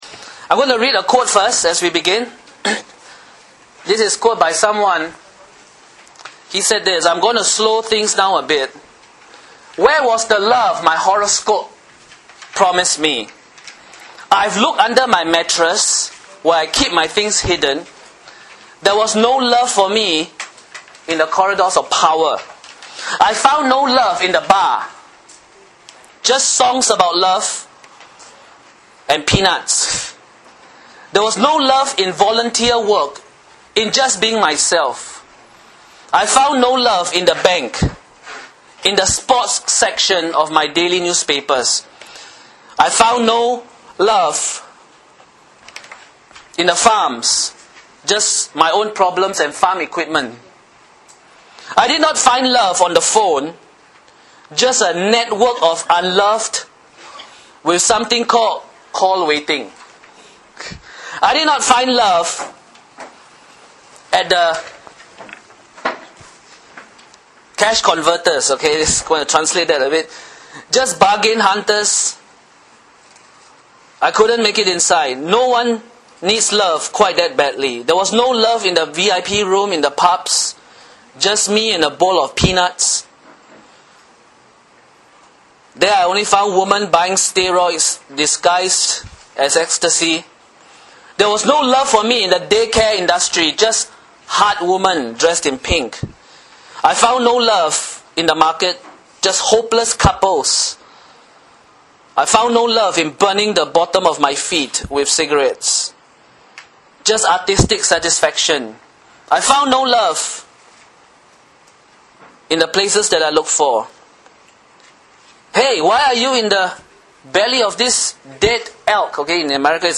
O Glorious Love Preached by